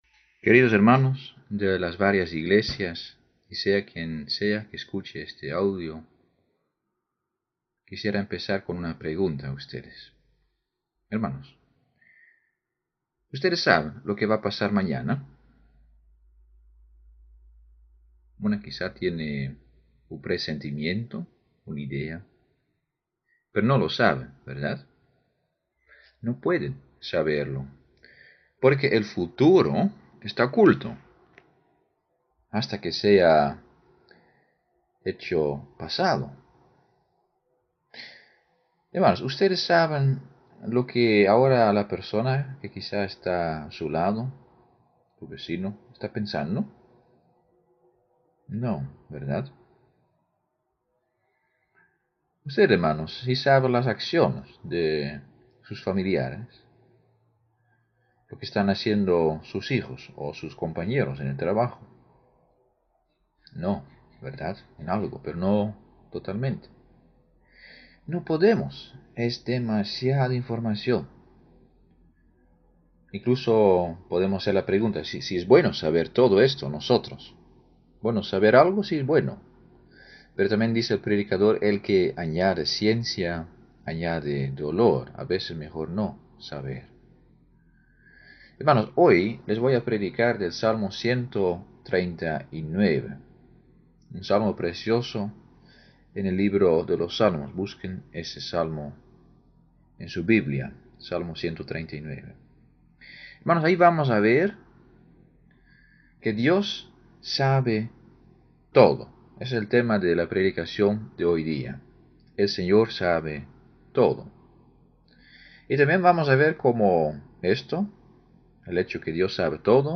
Salmo 139 Tipo: Sermón Bible Text